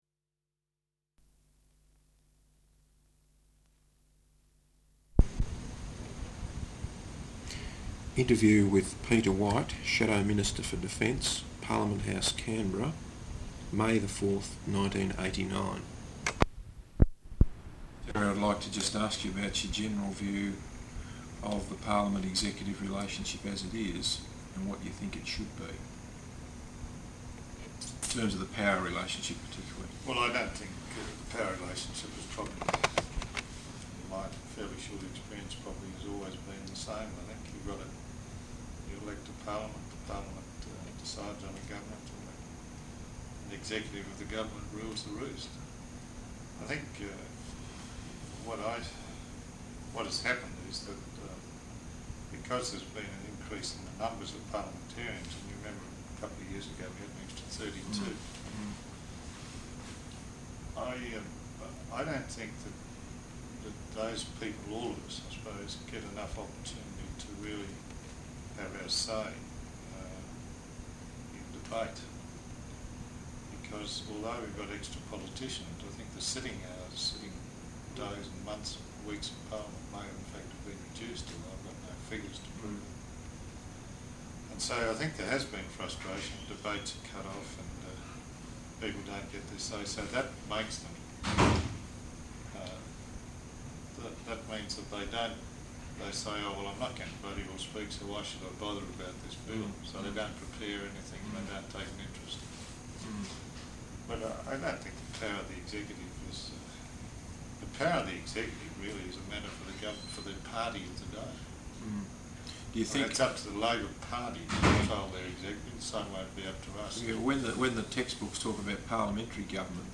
Interview with Peter White, Shadow Minister for Defence, at Parliament House, Canberra, on 4 May, 1989.